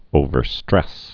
(ōvər-strĕs)